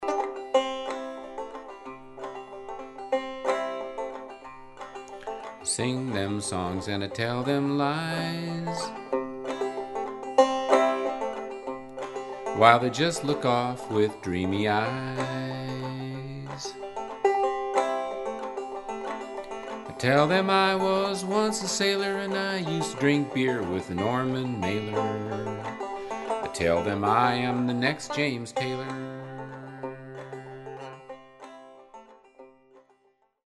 bringing a touch of classical class to the folk music idiom.